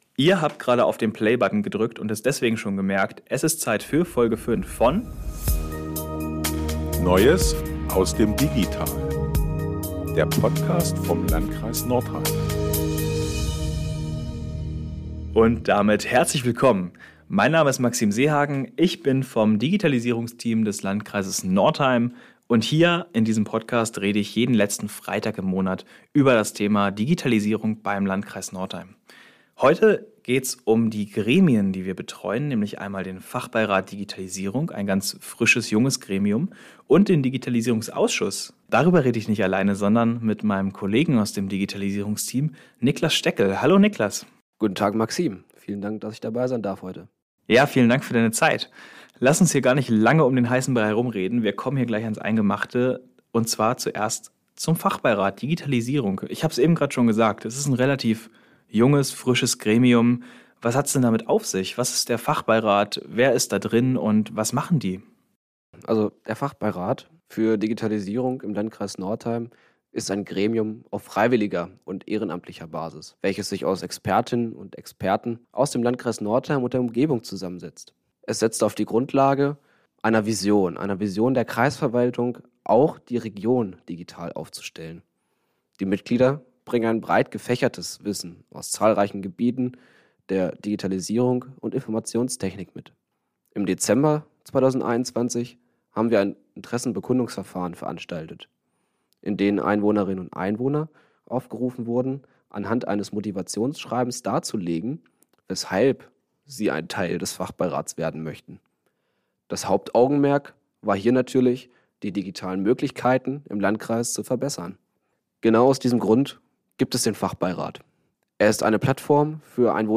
Außerdem kommen Mitglieder des Fachbeirates zu Wort und erläutern ihre Ansichten zum Projekt.